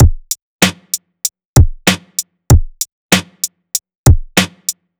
FK096BEAT1-L.wav